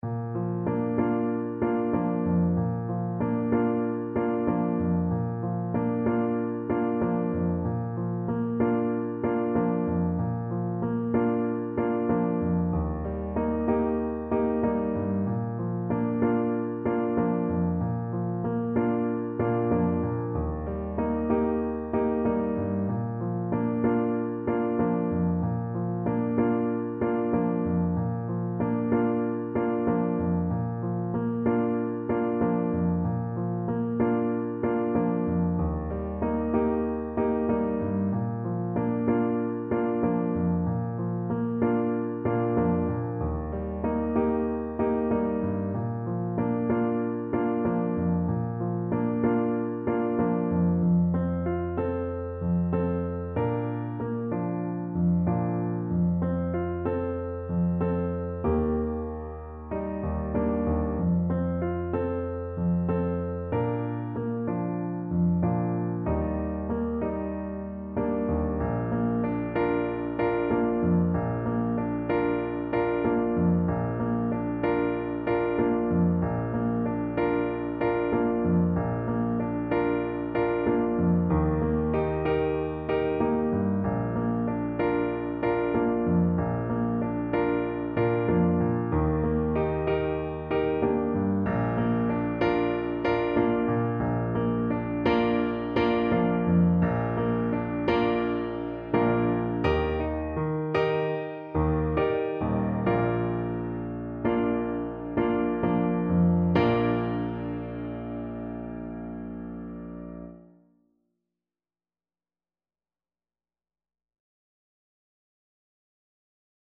World Africa Burkina Faso Diarra Loro Lora
Trumpet
Bb major (Sounding Pitch) C major (Trumpet in Bb) (View more Bb major Music for Trumpet )
Gently =c.126
4/4 (View more 4/4 Music)
Traditional (View more Traditional Trumpet Music)